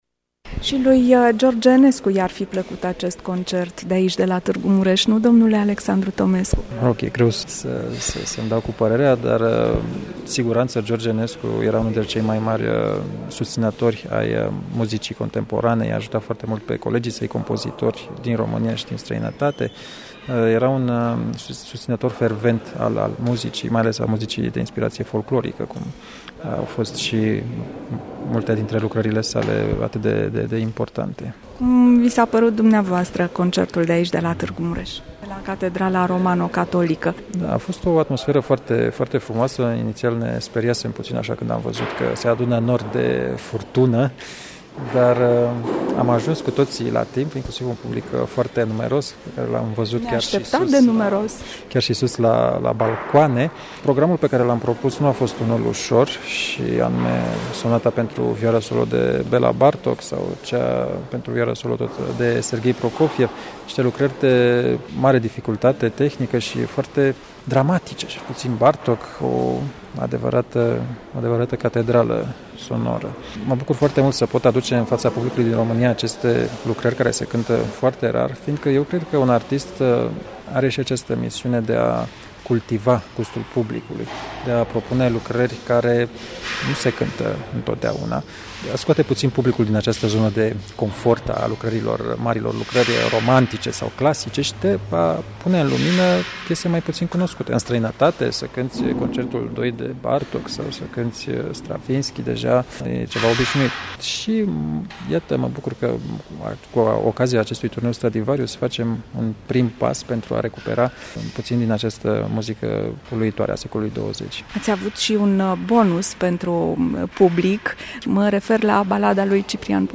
Interviu-Alexandru-Tomescu-.mp3